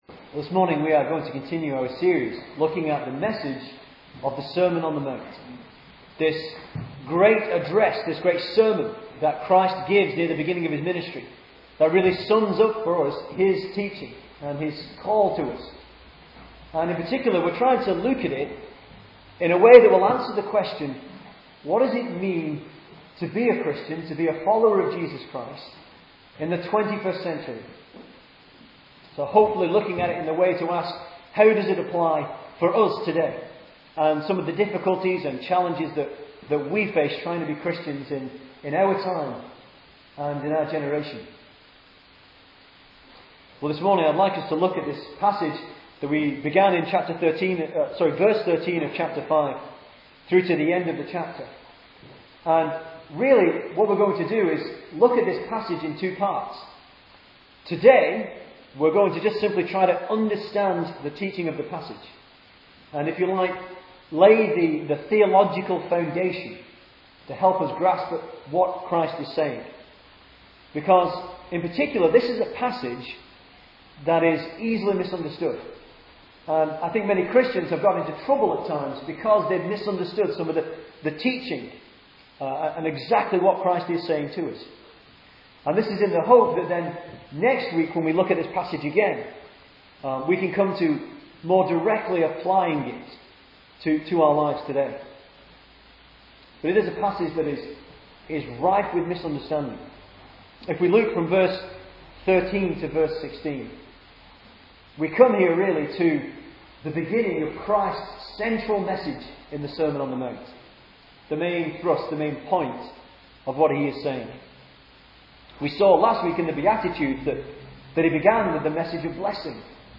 2010 Service Type: Sunday Morning Speaker